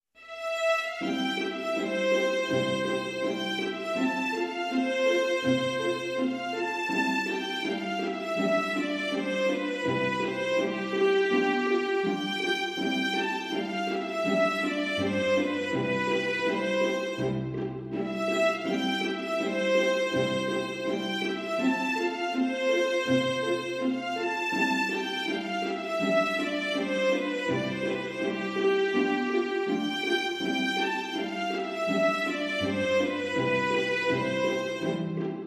Haydn-StringsQuartet-No17-Serenade.mp3